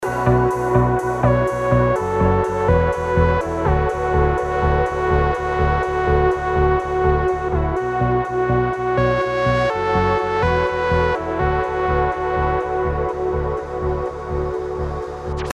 Как нарулить такой звук...(типа Муг)